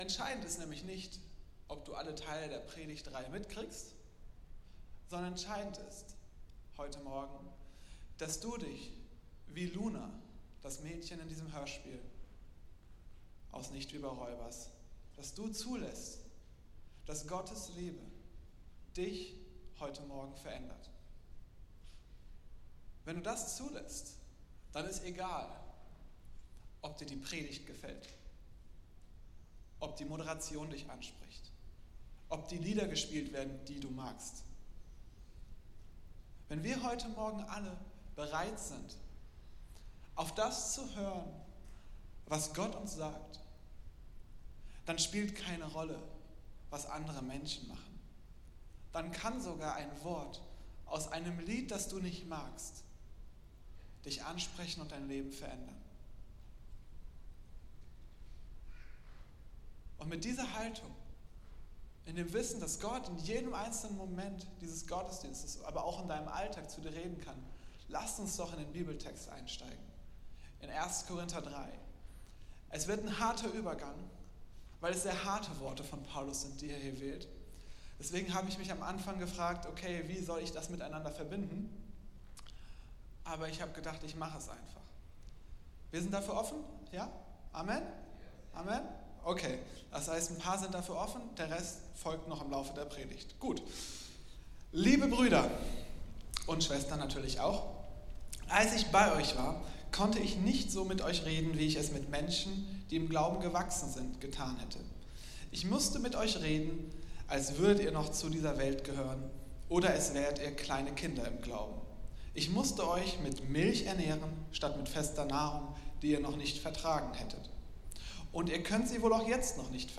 Predigt-31.07.mp3